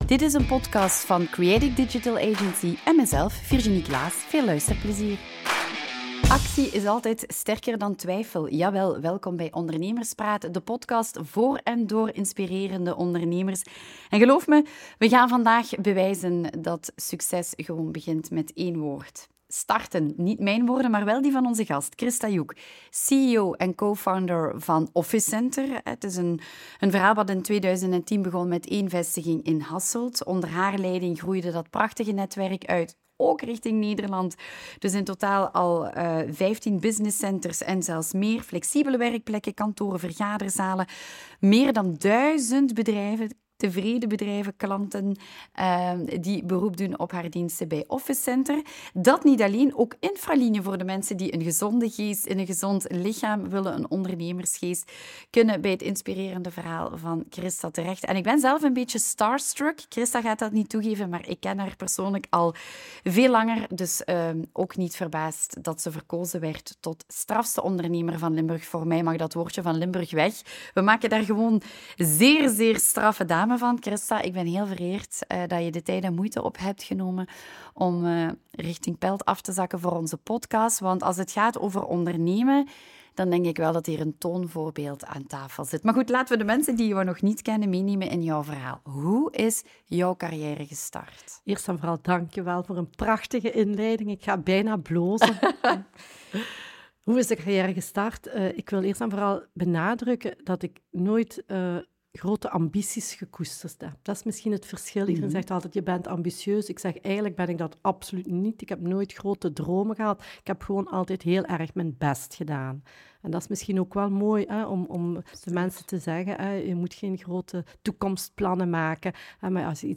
Audio narration of: Entrepreneurship: The Courage to Act and Build